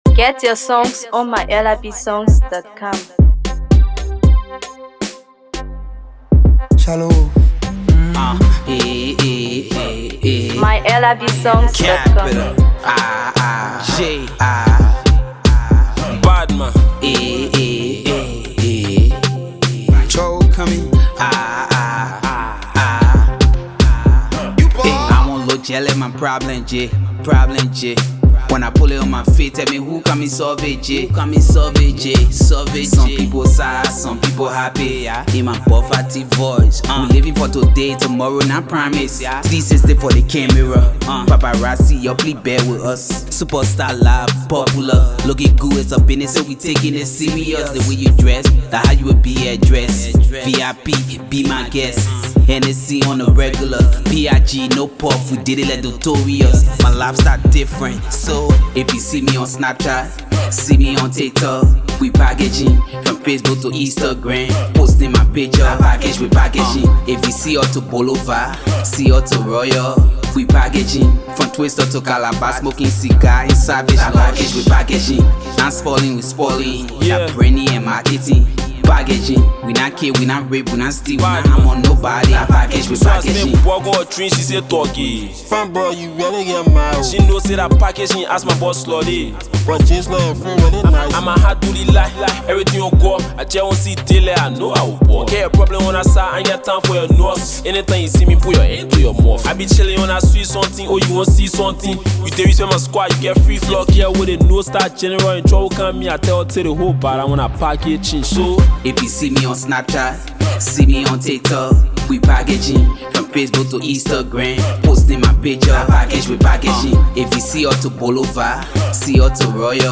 Afro PopHip HopMusic